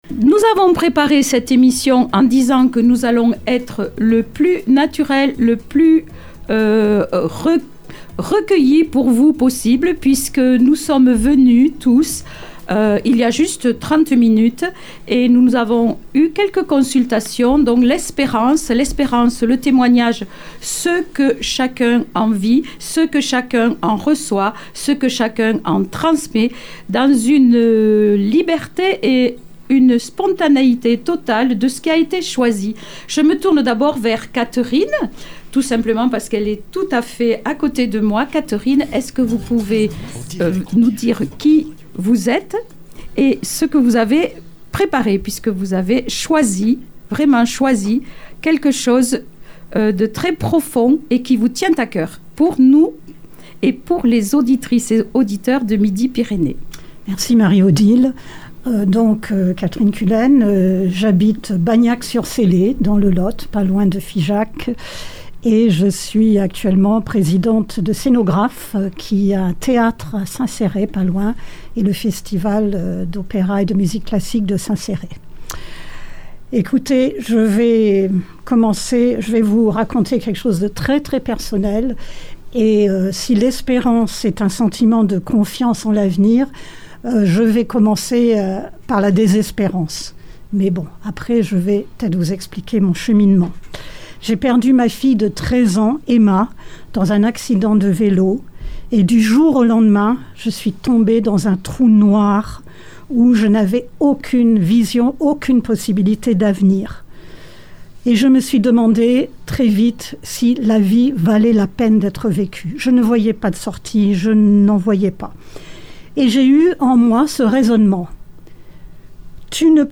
Voici laTable Ronde en direct de Figeac du Jeudi